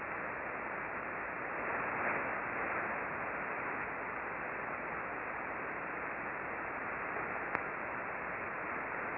We used the Icom R-75 HF Receiver tuned to 20.408 MHz (LSB).
Click here for a 9 second recording of the bursting at 1038